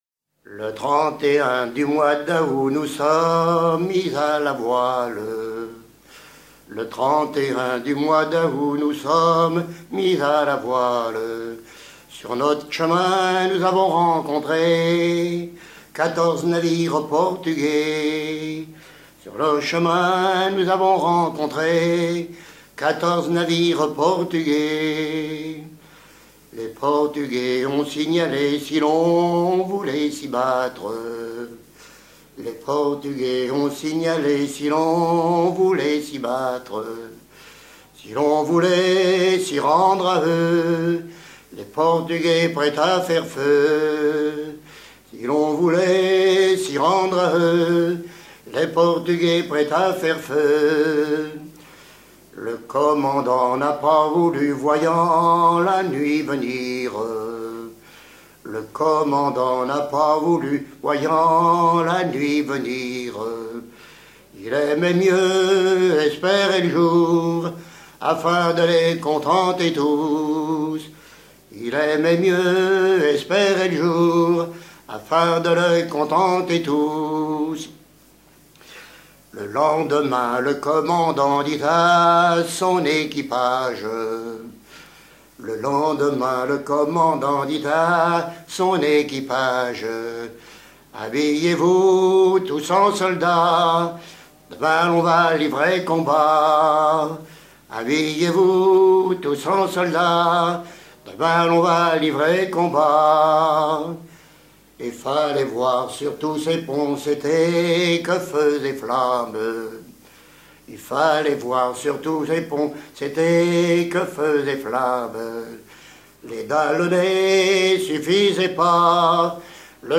Enregistré à Saint-Hilaire-de-Rier en 1986
Genre strophique
Pièce musicale éditée